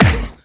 VEC3 Percussion 034.wav